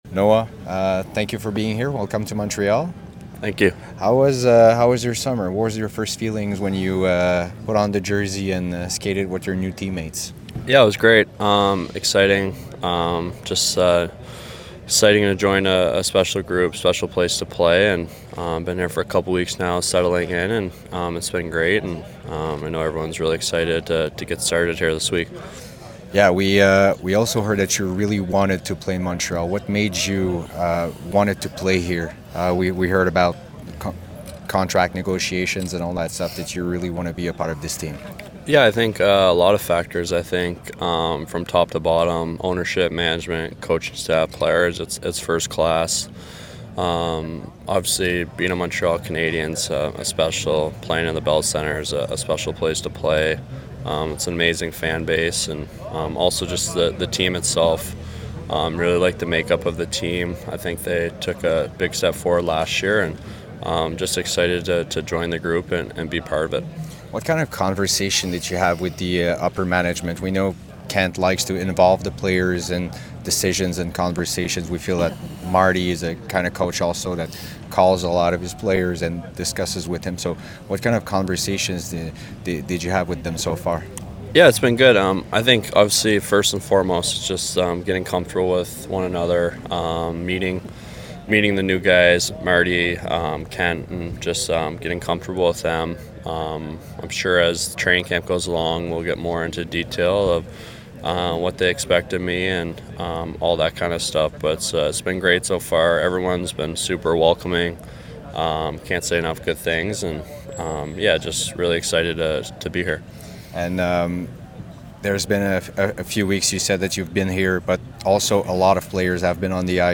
Entrevue exclusive avec Noah Dobson